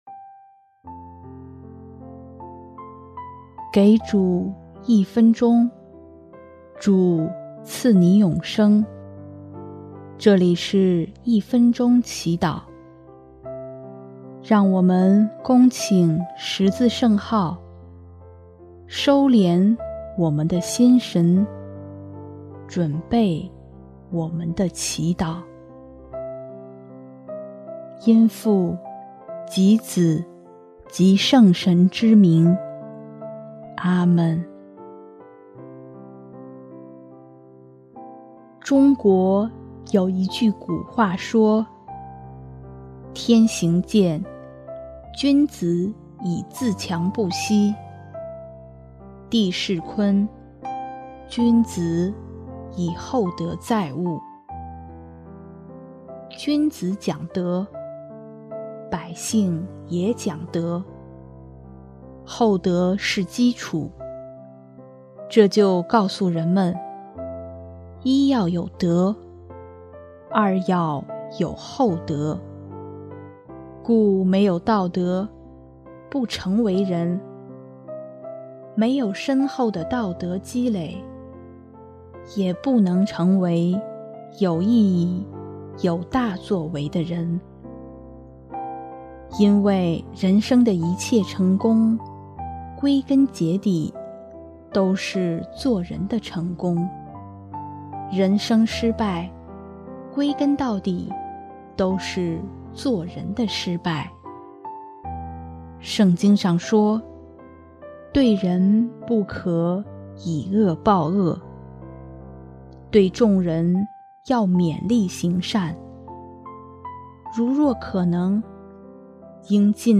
音乐： 第三届华语圣歌大赛参赛歌曲《指引》